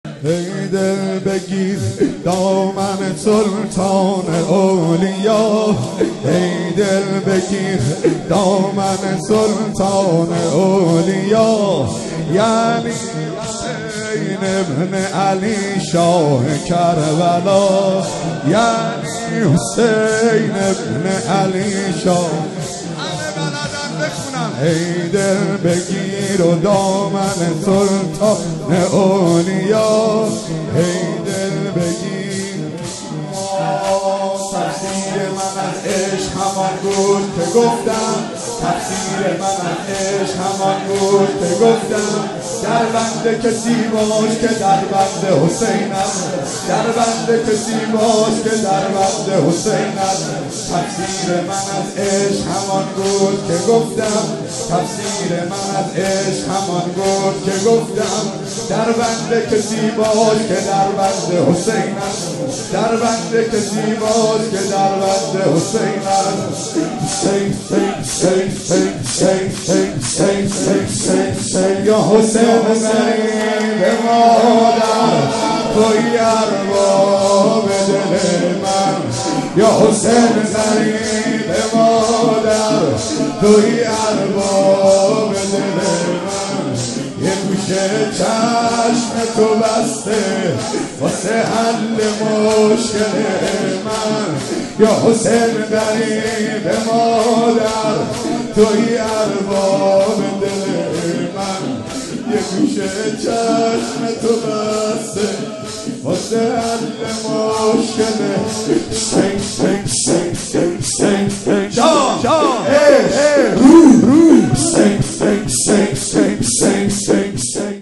شب بیستم رمضان ۹۷
مداحى